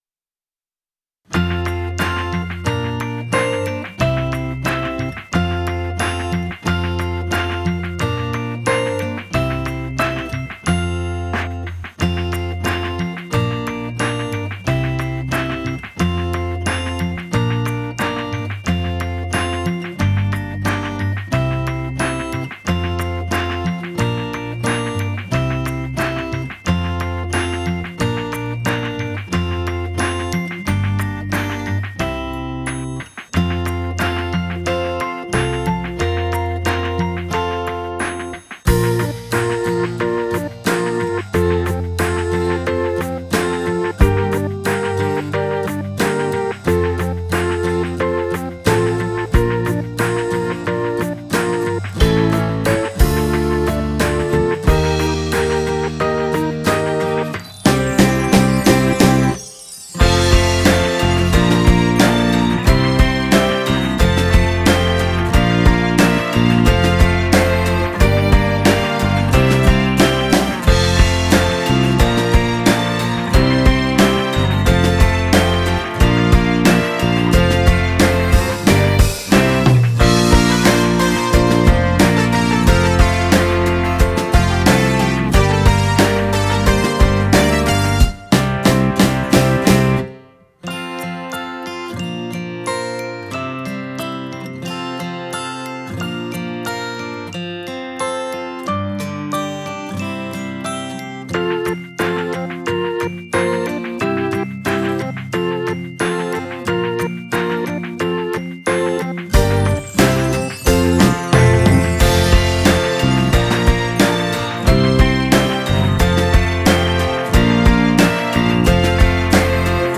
※「心つないで」音源（カラオケVer.)（WAV：49,877KB）
karaoke.wav